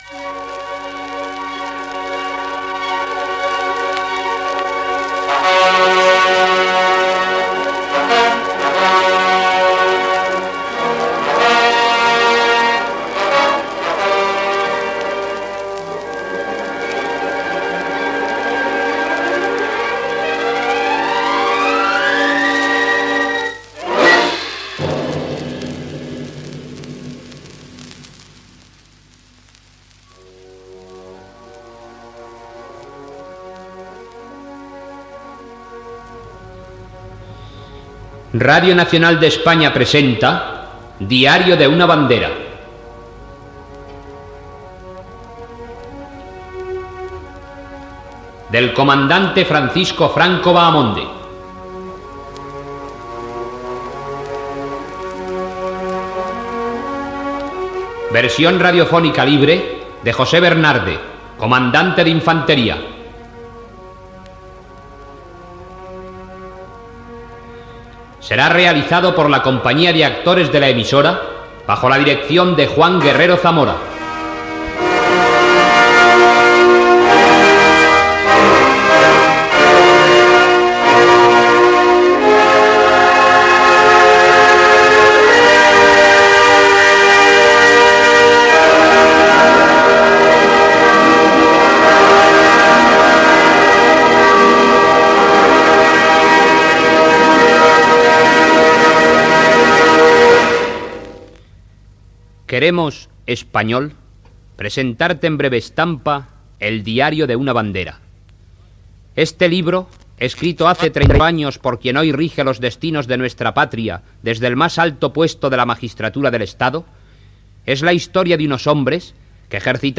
Adaptació radiofònica
Careta del programa i primeres escenes de l'obra
Gènere radiofònic Ficció